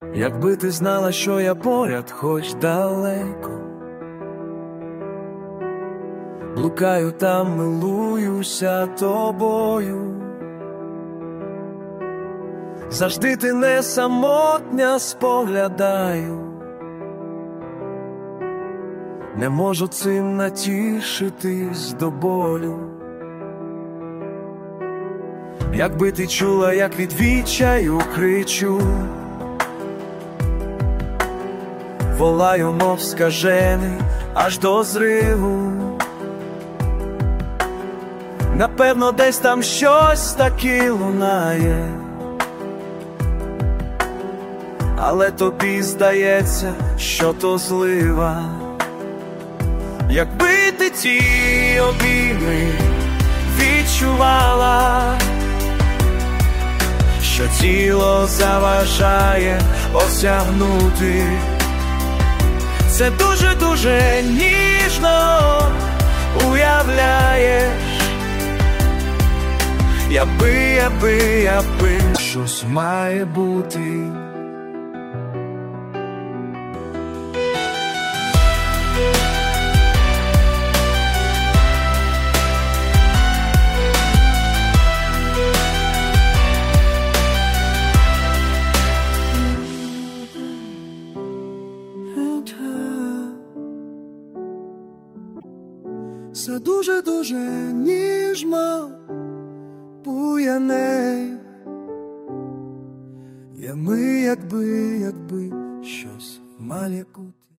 Текст - автора, мелодія і виконання - штучні
ТИП: Пісня
СТИЛЬОВІ ЖАНРИ: Ліричний